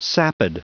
Prononciation du mot sapid en anglais (fichier audio)
Prononciation du mot : sapid